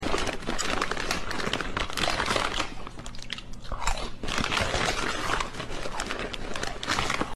Cat.